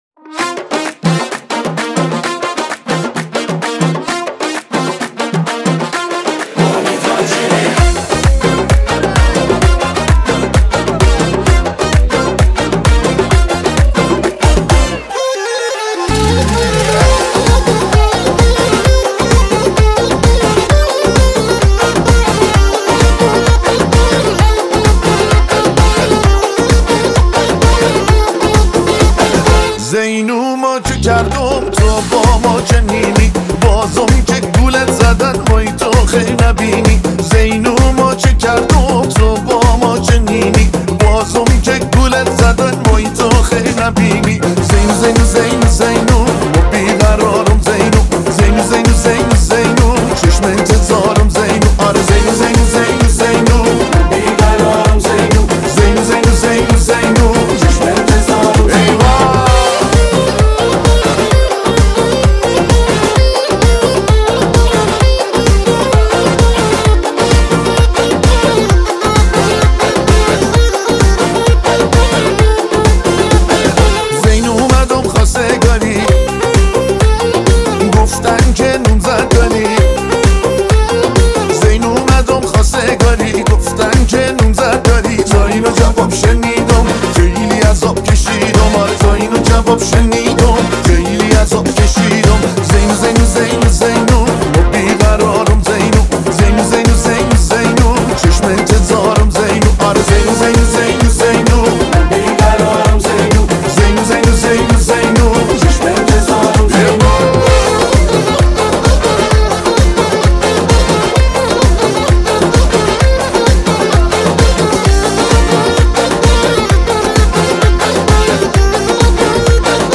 Категория: Иранские